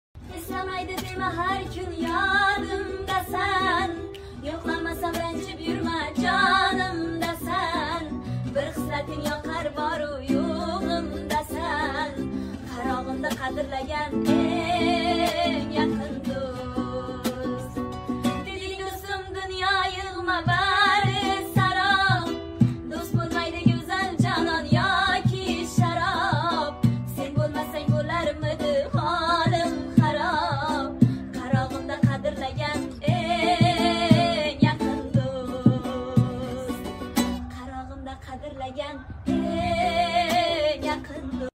qizlar ijrosida cover